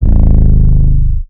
BWB WAV 7 808 (2).wav